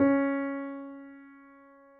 Added more instrument wavs
piano_049.wav